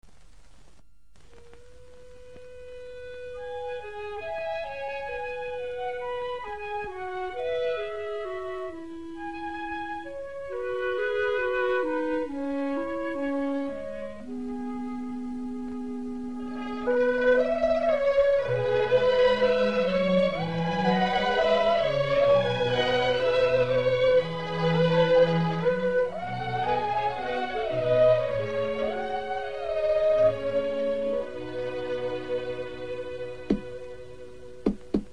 影片音乐运用河北地方音乐的素材，将如火如荼的抗日烽火，生动地再现给观众。